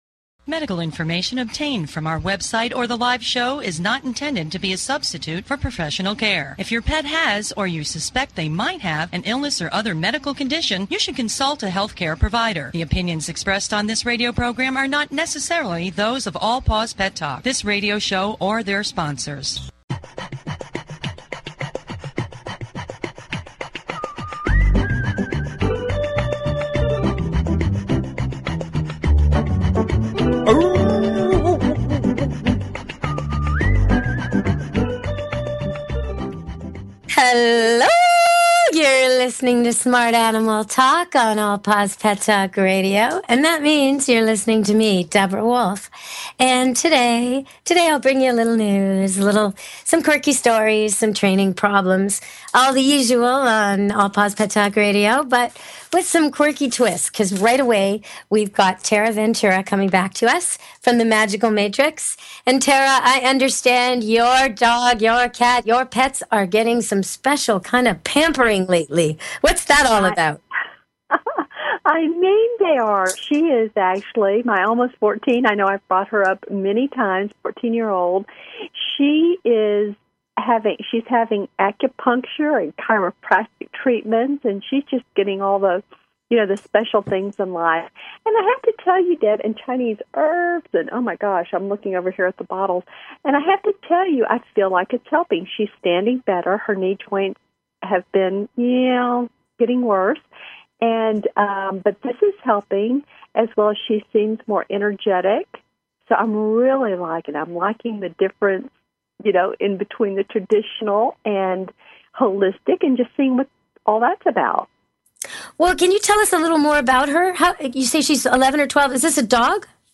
Talk Show Episode, Audio Podcast, All_Paws_Pet_Talk and Courtesy of BBS Radio on , show guests , about , categorized as
Our hosts are animal industry professionals covering various specialty topics and giving free pet behavior and medical advice. We give listeners the opportunity to speak with animal experts one on one. Our show offers the audience exciting updates about events and entertainment opportunities taking place in their communities.